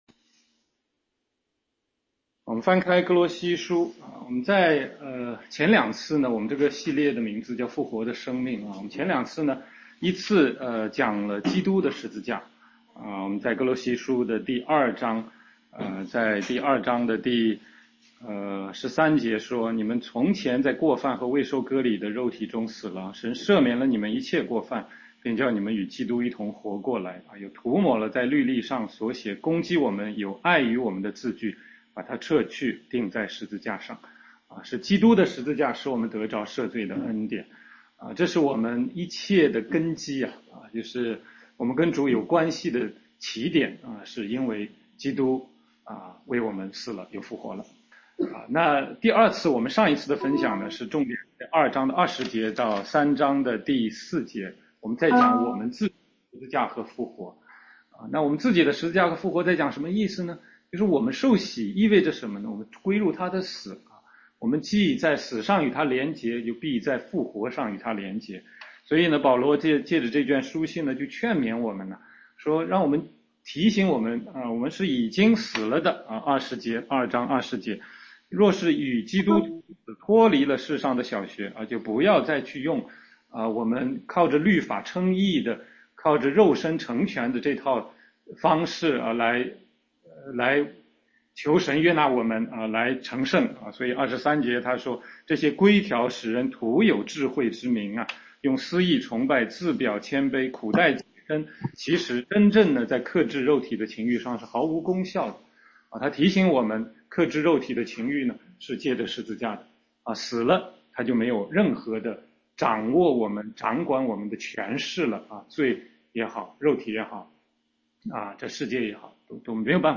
16街讲道录音 - 复活的生命（3）